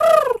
pgs/Assets/Audio/Animal_Impersonations/pigeon_2_call_09.wav at master
pigeon_2_call_09.wav